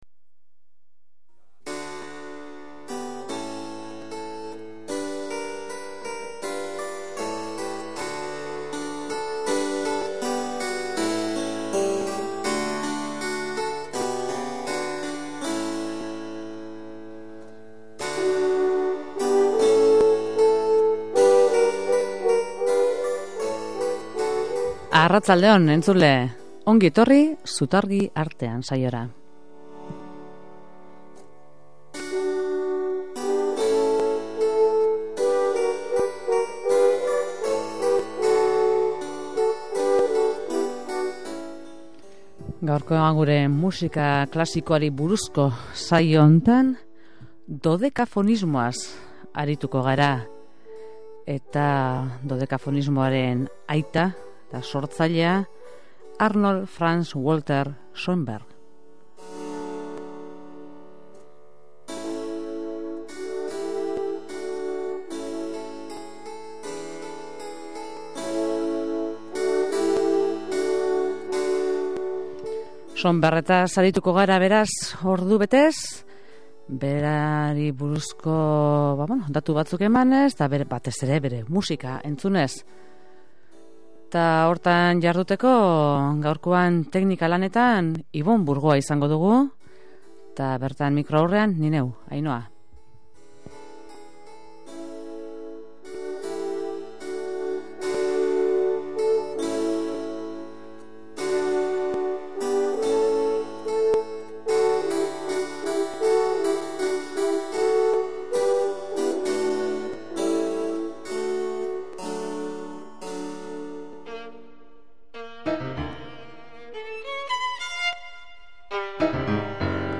Irratsaioan Schoenbergen bi garaien joaldiak eskaintzen dira.